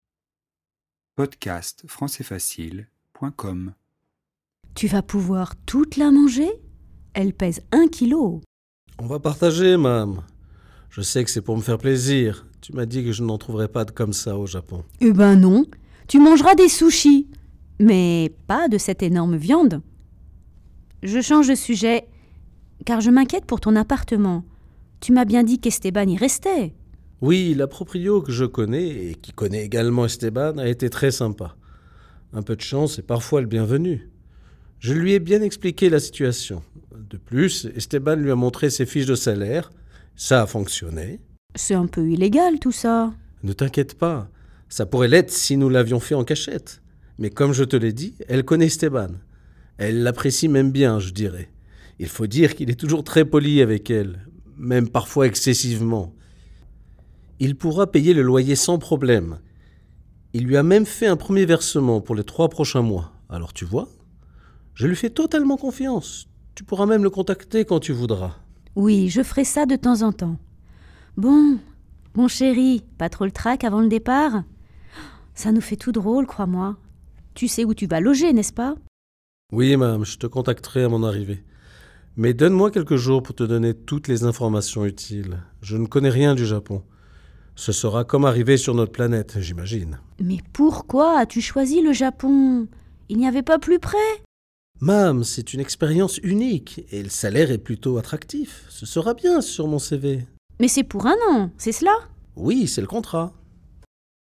Ce dialogue est en français familier et ne respecte pas toutes les règles de la grammaire écrite.
🔹Dialogue